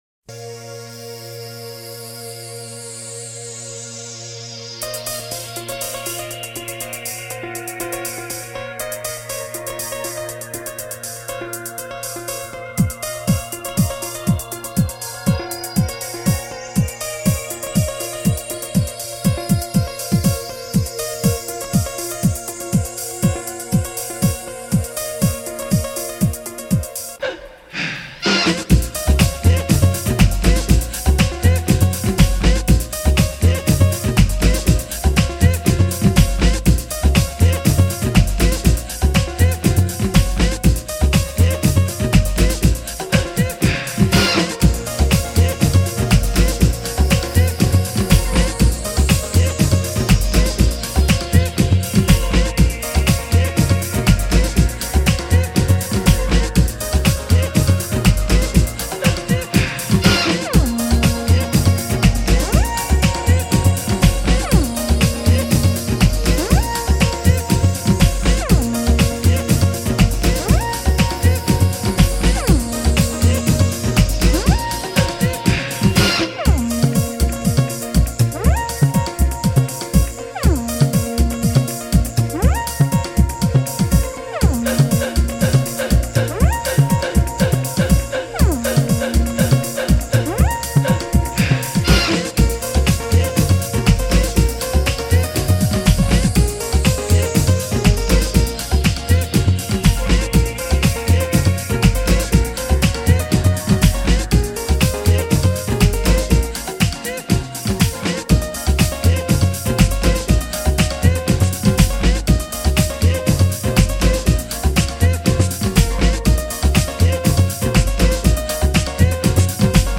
Salsa House